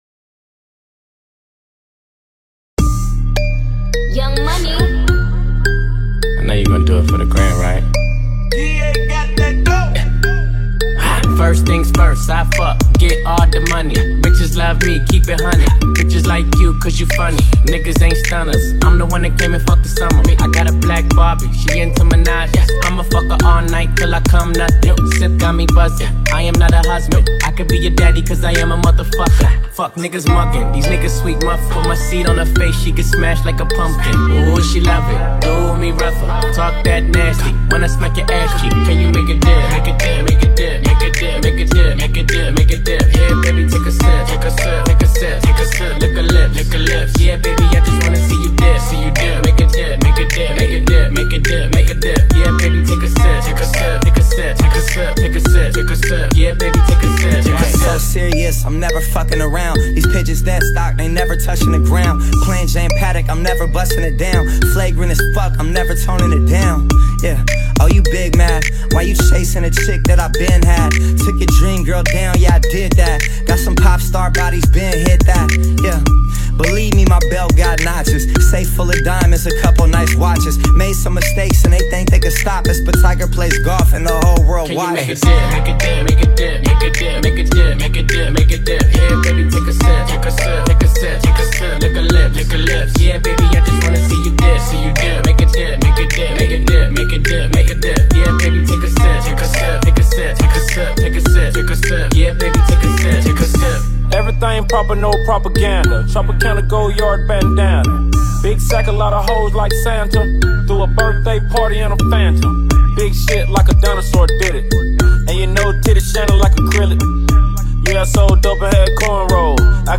ریمیکس شاد
رپ آمریکایی